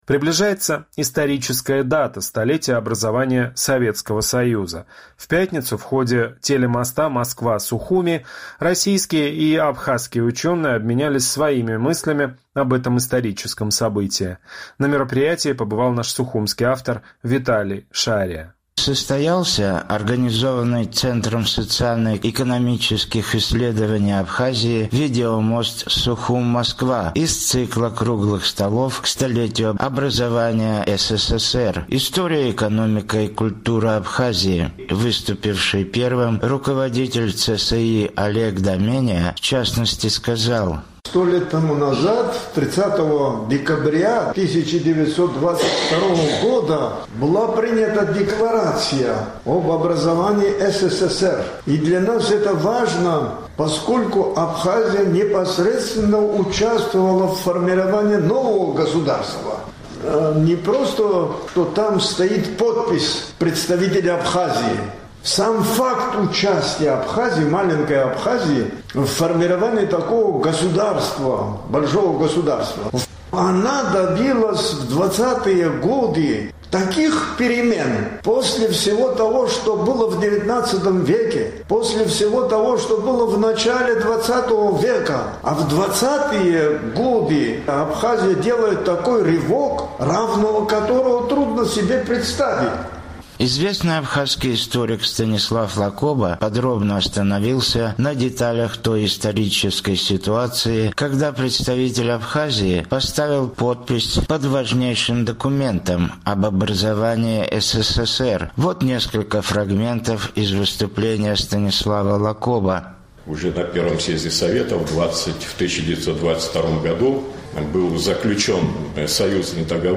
Сегодня состоялся организованный Центром социально-экономических исследований Абхазии видеомост «Сухум – Москва» из цикла круглых столов «К 100-летию образования СССР (история, экономика и культура Абхазии)».